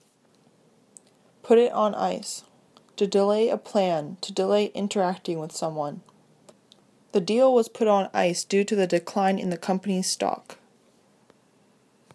英語ネイティブによる発音は下記のリンクをクリックしてください。
putonice.mp3